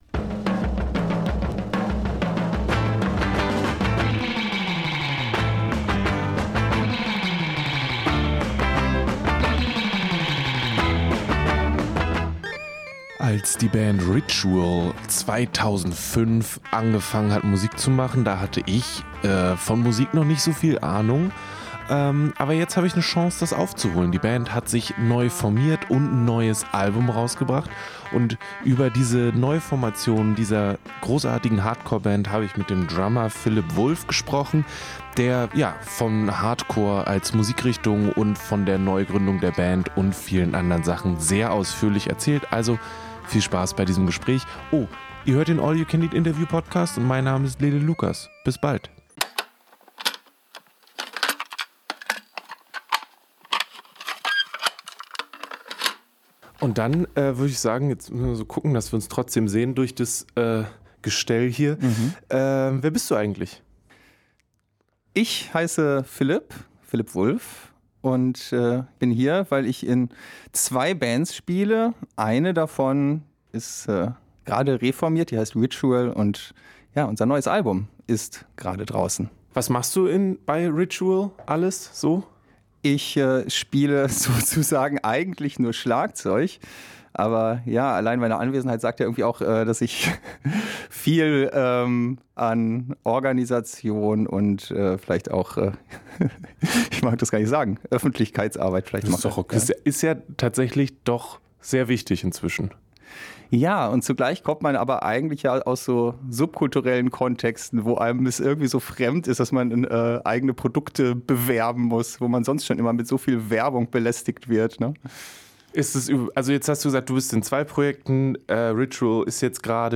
songs-for-the-haunted-interview-mit-ritual.mp3